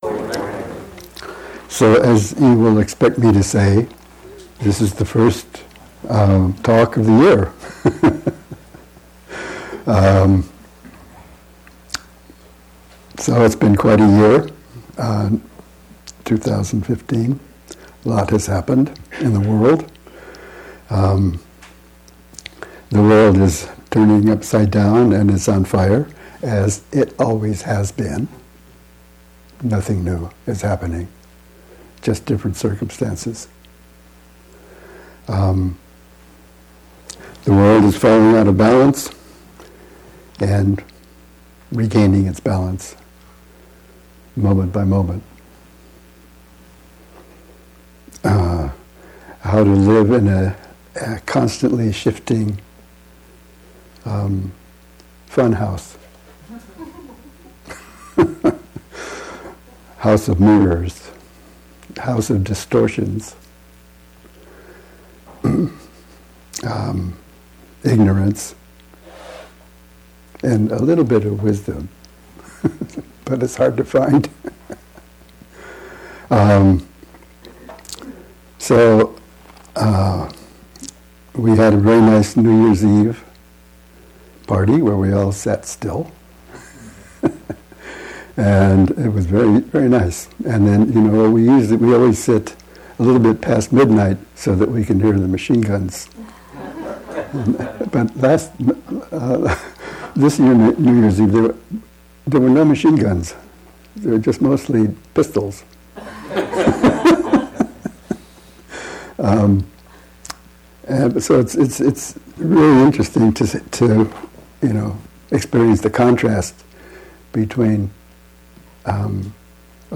Welcome to the Berkeley Zen Center’s online library of public lectures, classes, and dharma talks.